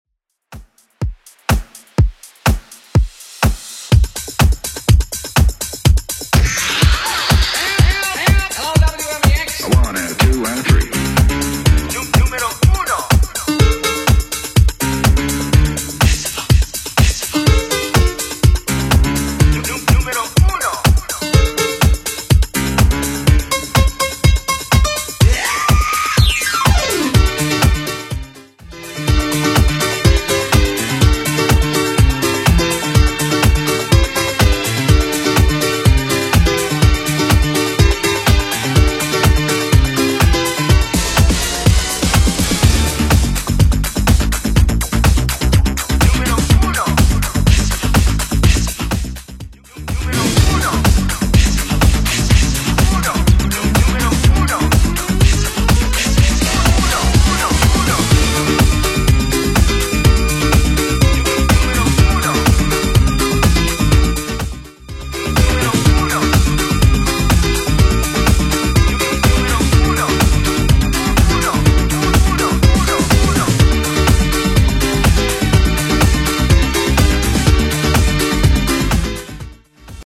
Genre: 80's